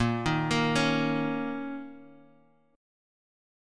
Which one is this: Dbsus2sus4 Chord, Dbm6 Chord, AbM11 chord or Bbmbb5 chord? Bbmbb5 chord